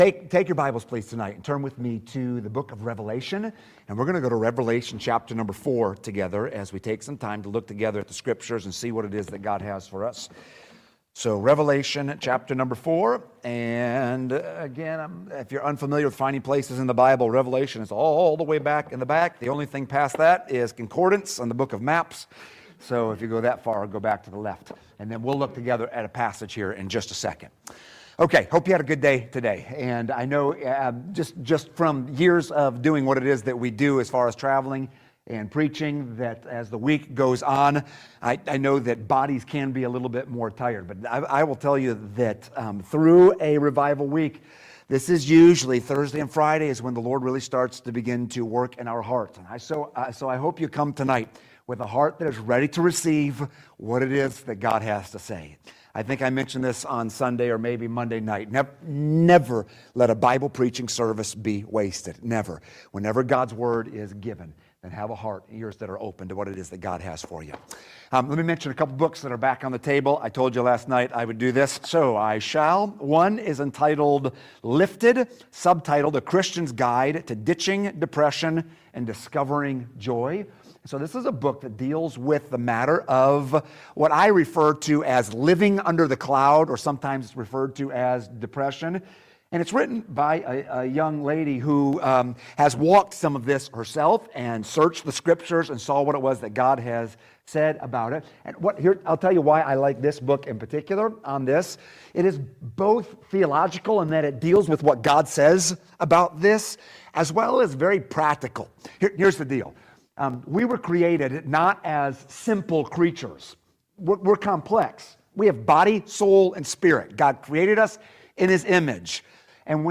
Rev. 4:10-11 Service Type: Adult Sunday School Class « Fellowship with God Nothing is Impossible with God!